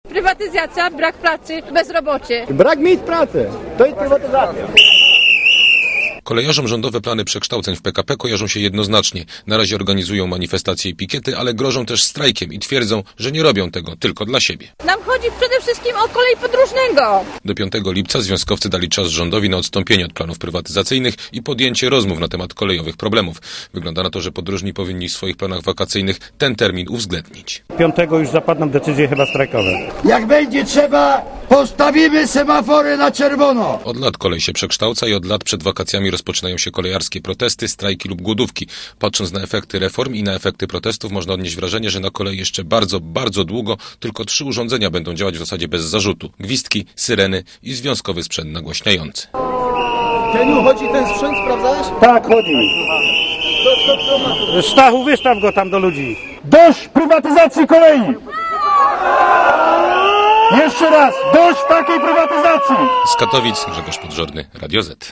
relacji
kolejarze_-_protest.mp3